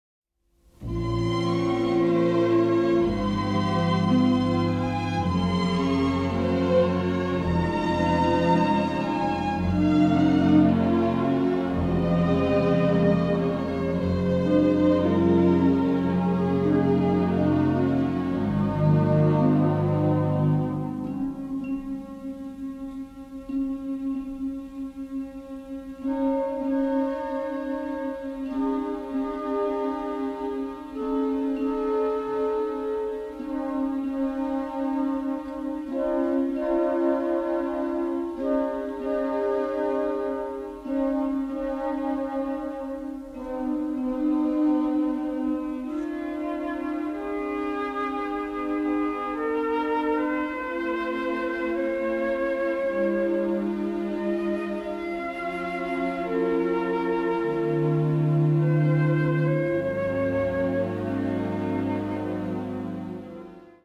score is lush and full of kaleidoscopic orchestral color